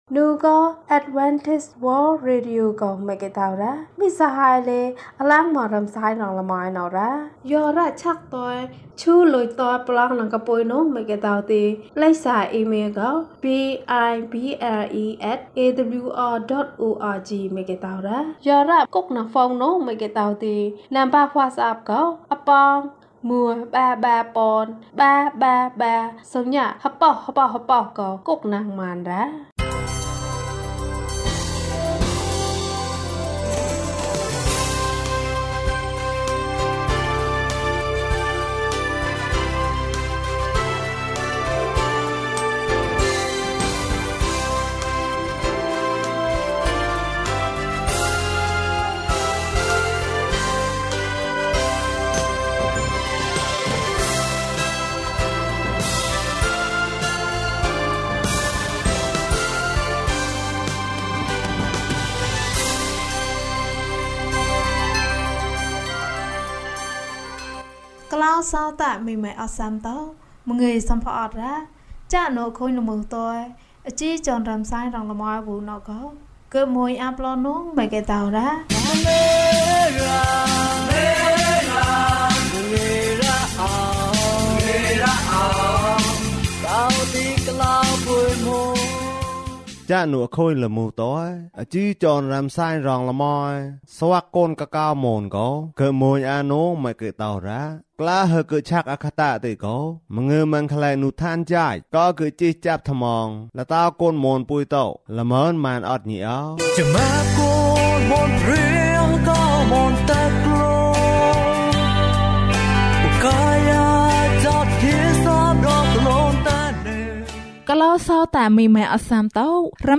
အလင်းရောင်ကို မြင်တယ်။ ကျန်းမာခြင်းအကြောင်းအရာ။ ဓမ္မသီချင်း။ တရားဒေသနာ။